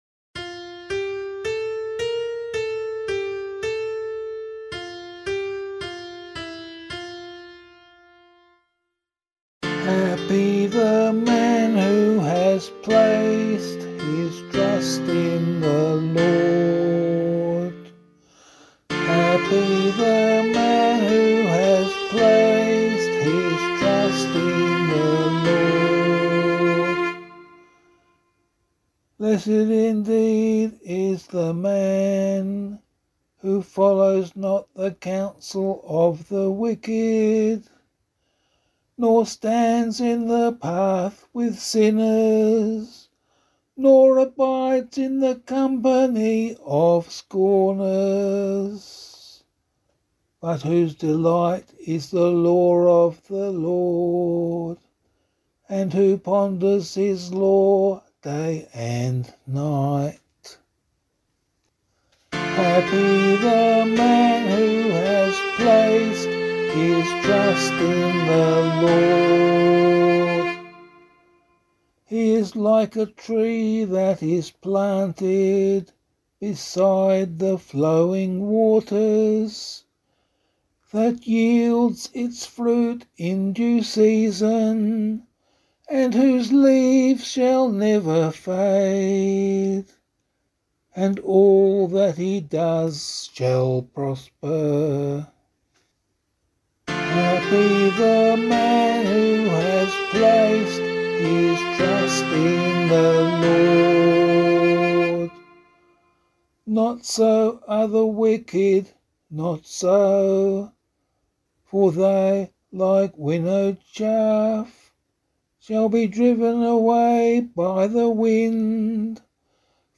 040 Ordinary Time 6 Psalm C [APC - LiturgyShare + Meinrad 1] - vocal.mp3